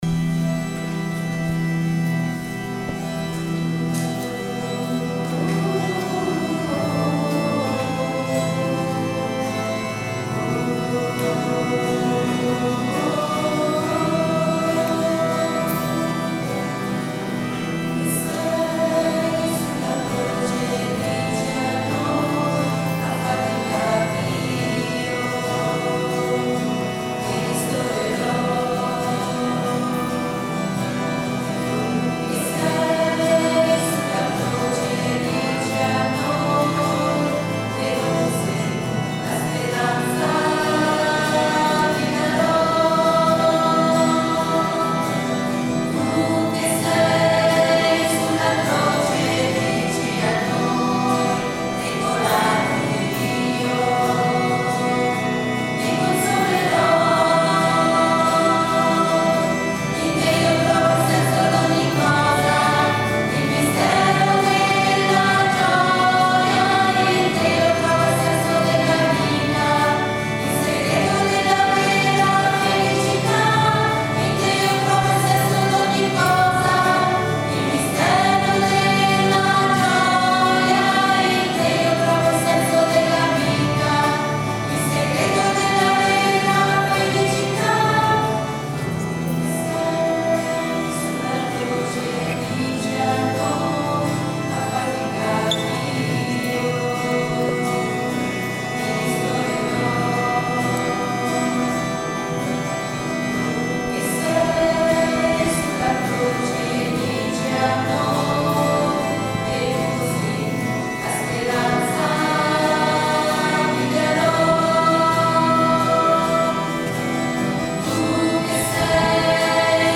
Vi Consolerò, Scola Cantorum
L’Azione liturgica che ha preceduta la processione, ha avuto il suo culmine nell’Adorazione della Croce.